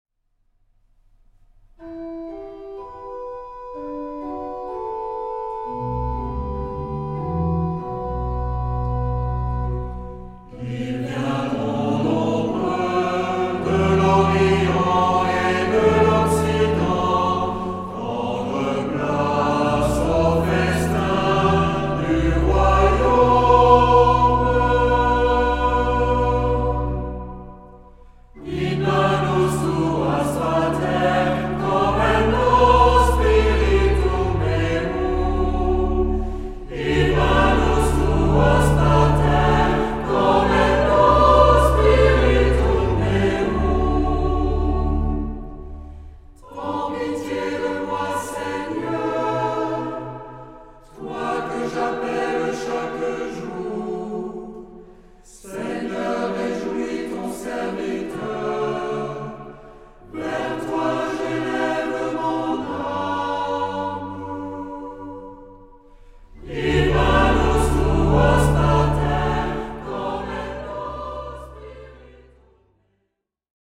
Genre-Style-Form: troparium ; Psalmody
Mood of the piece: collected
Type of Choir: SAH  (3 mixed voices )
Instruments: Organ (1) ; Melody instrument (optional)
Tonality: D major ; B minor